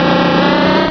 pokeemerald / sound / direct_sound_samples / cries / beedrill.aif